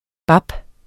Udtale [ ˈbɑb ]